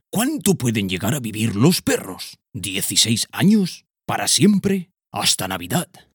TEST HISTORIA PERRO-Narrador-02_0.mp3